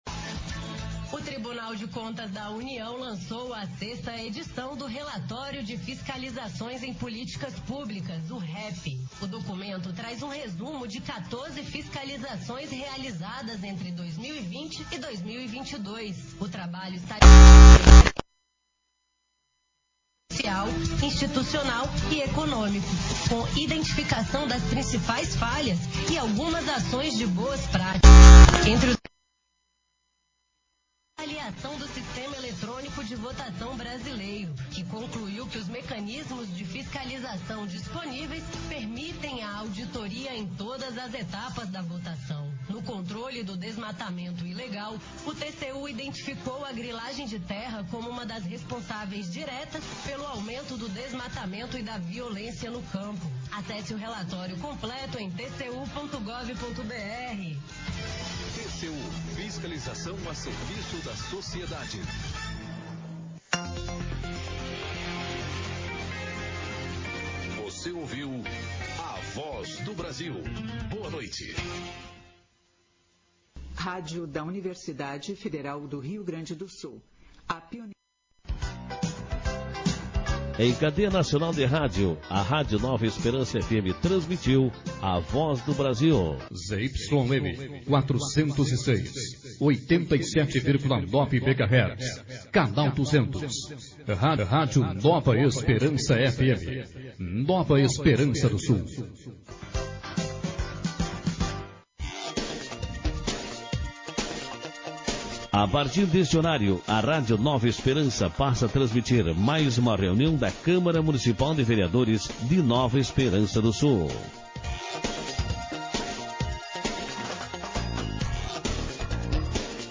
Sessão Ordinária 43/2022